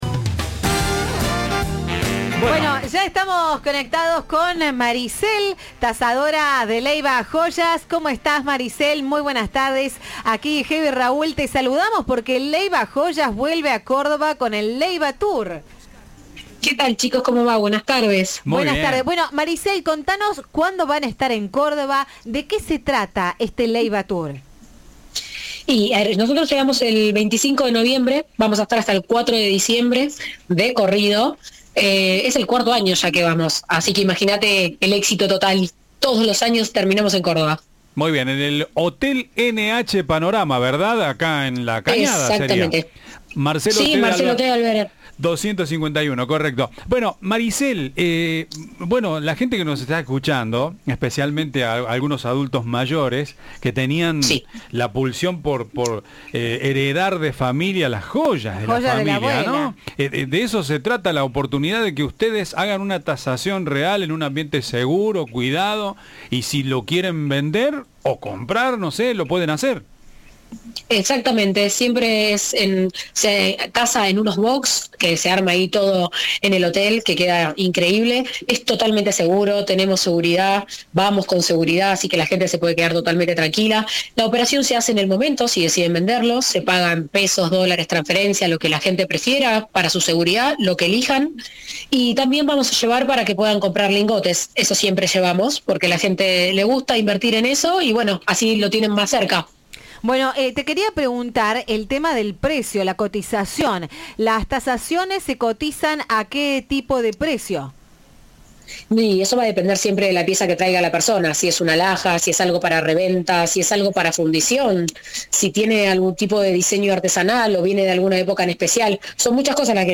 Entrevista de Viva la Radio.